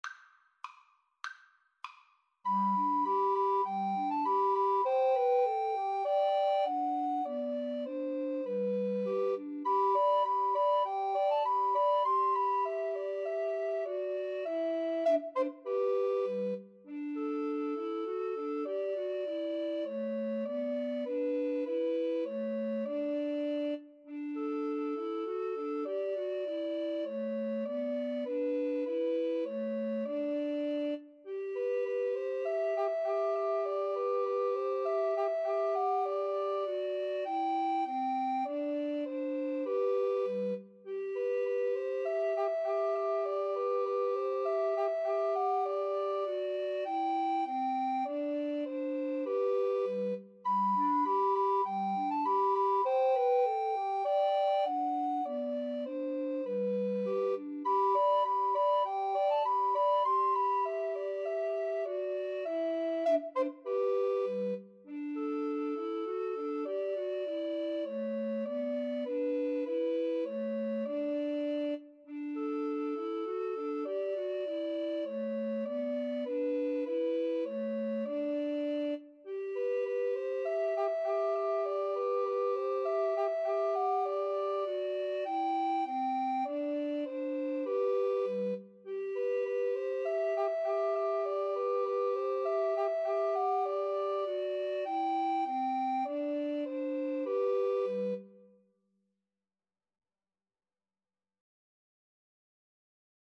Free Sheet music for Recorder Trio
G major (Sounding Pitch) (View more G major Music for Recorder Trio )
2/2 (View more 2/2 Music)
Traditional (View more Traditional Recorder Trio Music)